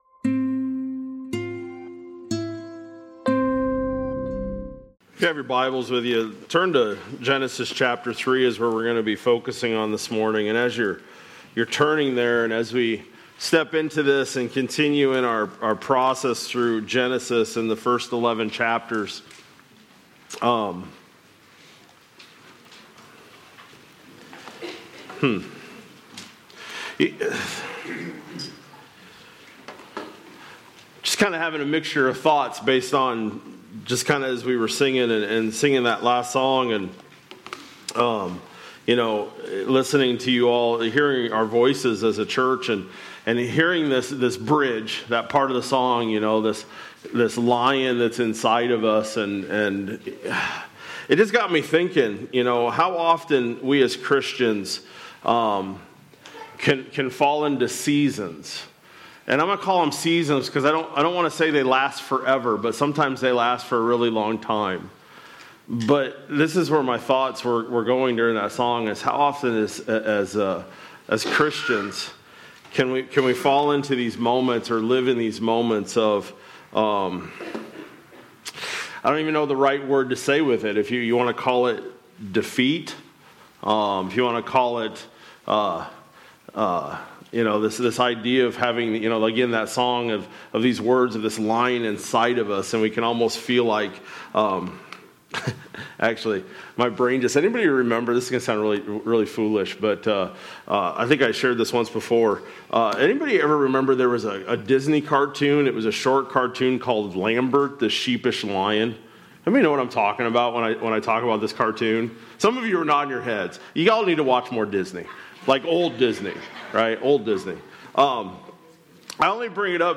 Feb-22-26-Sermon-Audio.mp3